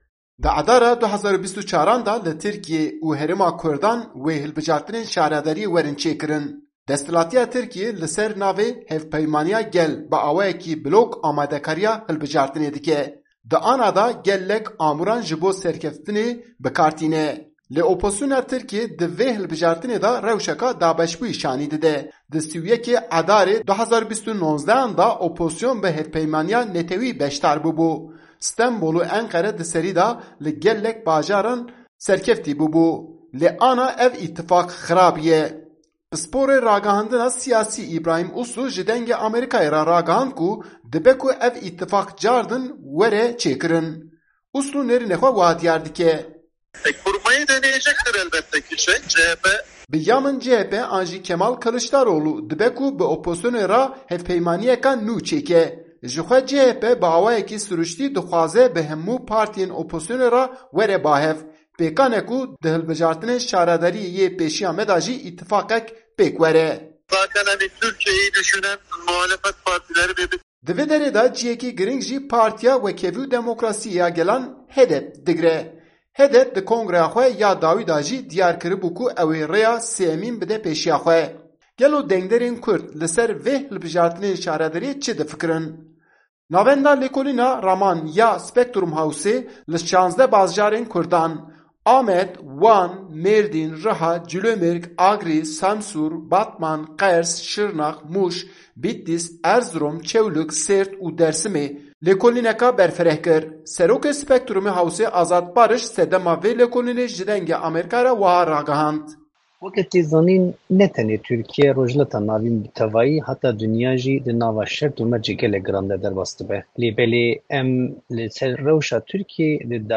HEDEP û Hilbijartinên Şaredariyan_Raporta Enqerê